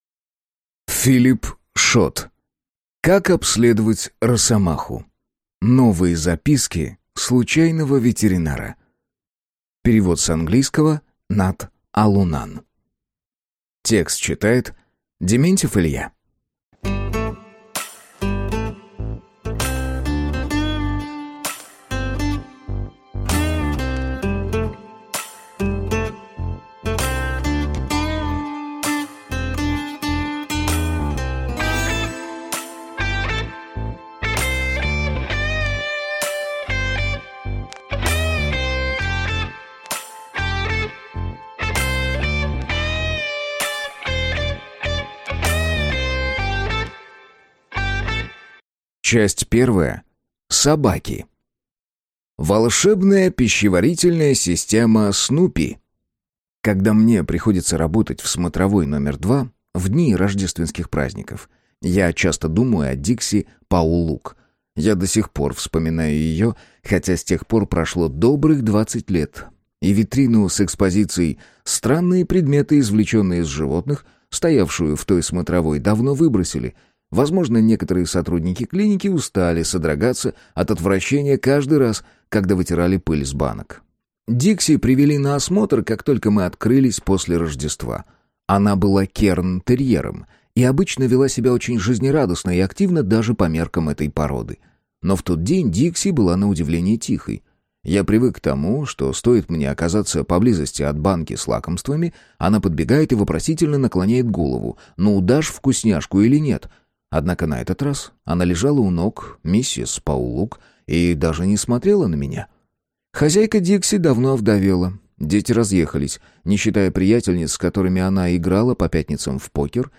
Аудиокнига Как обследовать росомаху. Новые записки случайного ветеринара | Библиотека аудиокниг